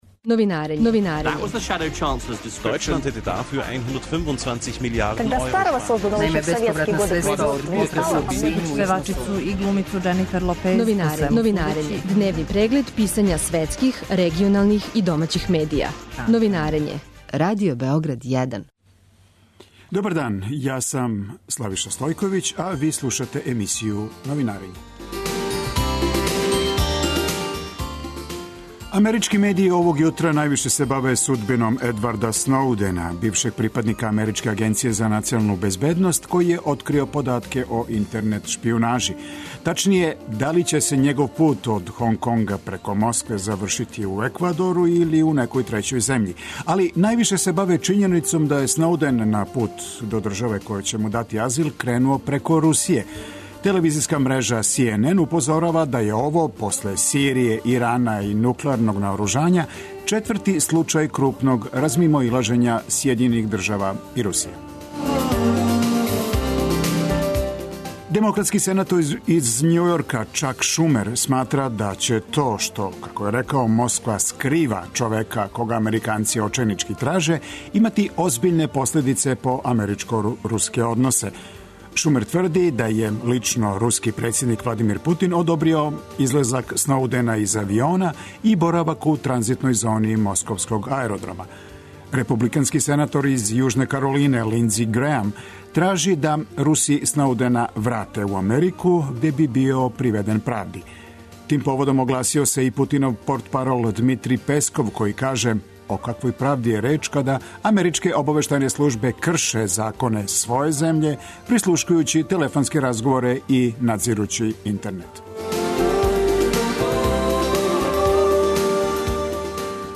Радио Београд 1, 09.40